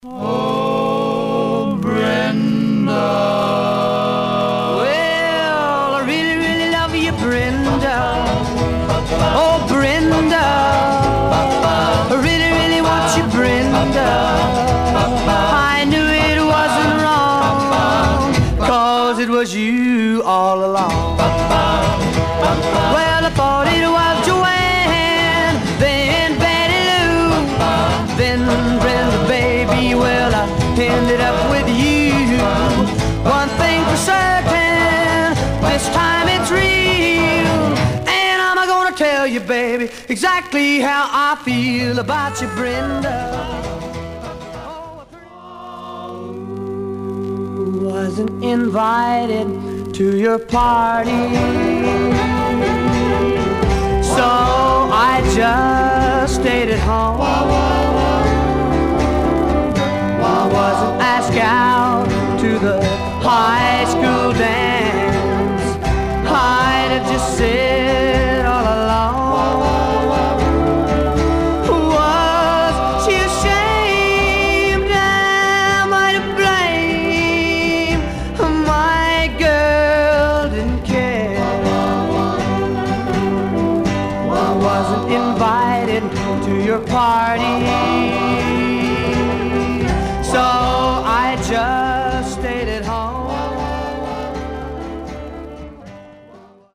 Some surface noise/wear Stereo/mono Mono